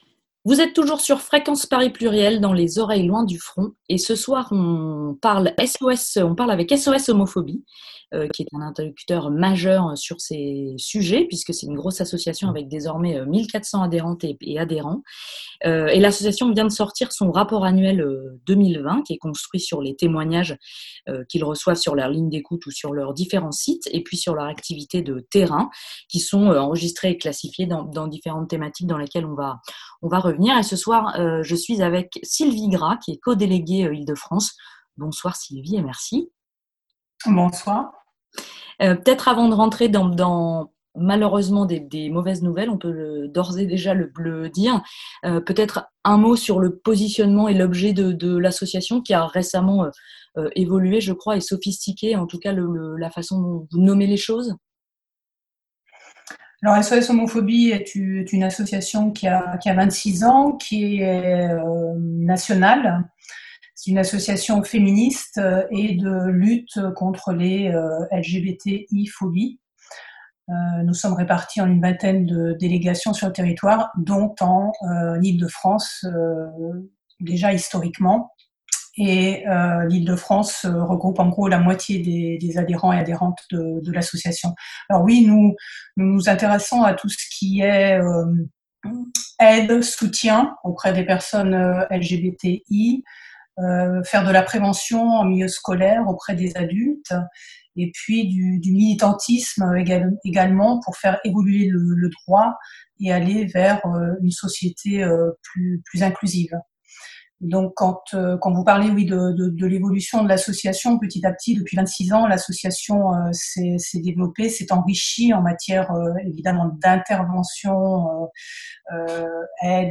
Cette semaine, on a interviewé une membre de SOS homophobie à l'occasion de la sortie du Rapport Annuel sur les LGBTIphobies. Des chiffres inquiétants et des témoignages saisissants.